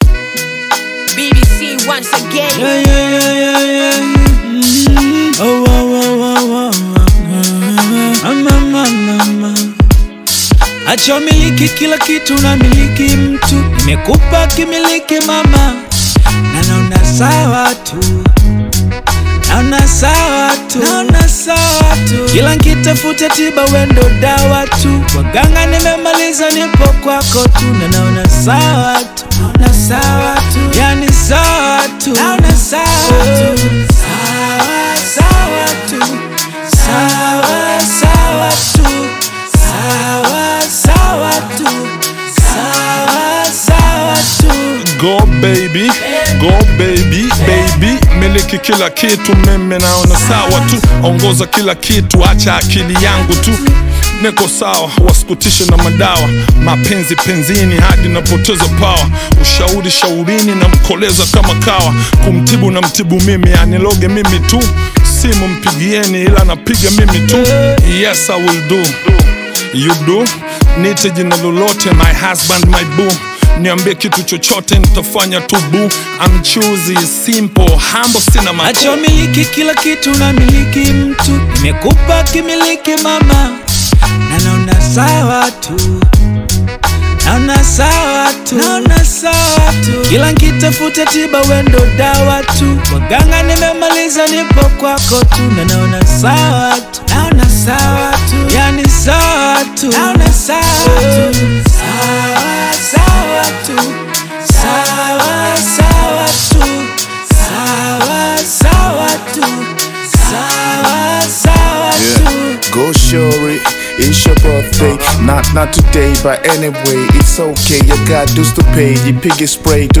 is a smooth, feel-good track
With its infectious hook and laid-back rhythm,
the ultimate chill-out anthem